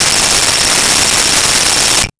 Cell Phone Ring 05
Cell Phone Ring 05.wav